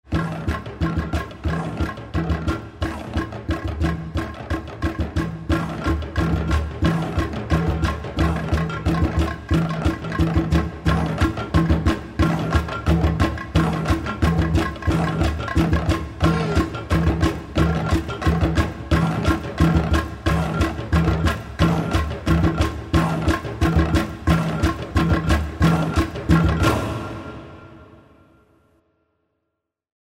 Soundtrack with an African Feel!
Tribal drums, moving panoramic
themes and atmospheres with beautiful African voices